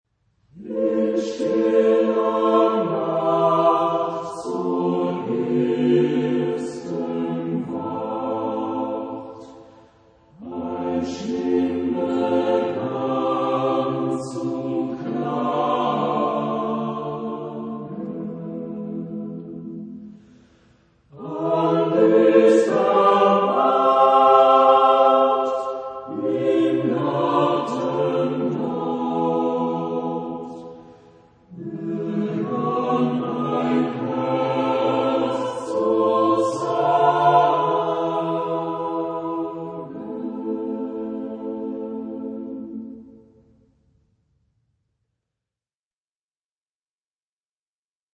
Genre-Stil-Form: Volkslied ; Liedsatz ; weltlich
Chorgattung: SATB  (4 gemischter Chor Stimmen )
Tonart(en): a-moll